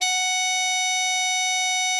bari_sax_078.wav